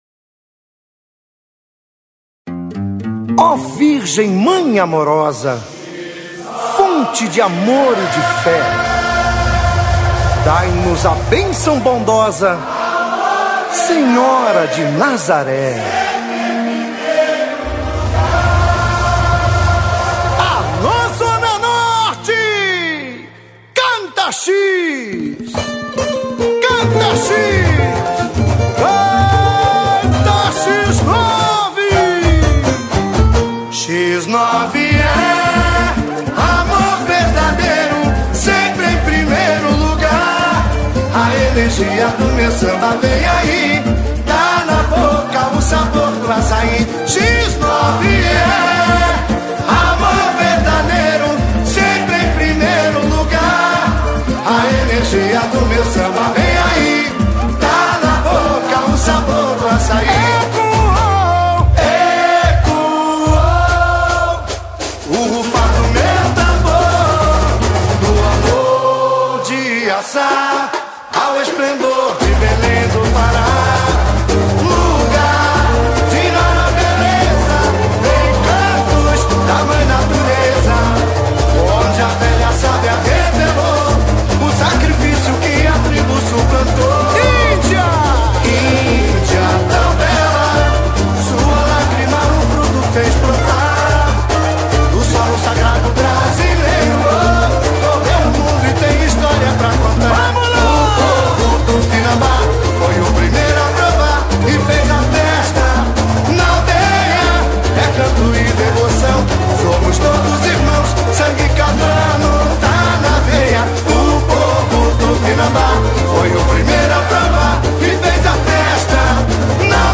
Puxador: